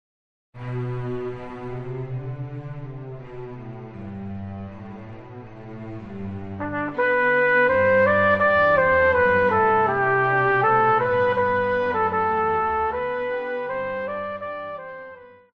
Classical
Trumpet
Orchestra
Instrumental
Only backing